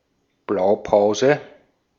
Ääntäminen
Vaihtoehtoiset kirjoitusmuodot (vanhahtava) blue print (vanhahtava) blue-print Synonyymit plan layout cyanotype road map schematic scheme Ääntäminen US Tuntematon aksentti: IPA : /ˈbluːˌpɹɪnt/ IPA : /.ˌprɪnt/